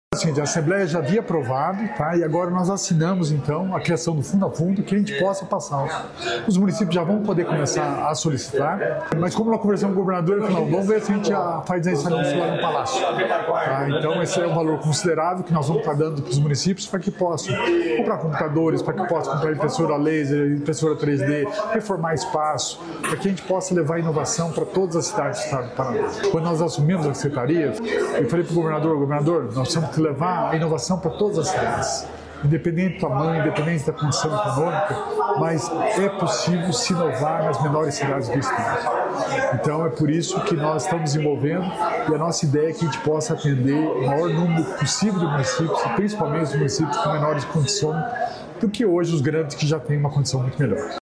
Sonora do secretário estadual da Inovação e Inteligência Artificial, Alex Canziani, sobre repasse fundo a fundo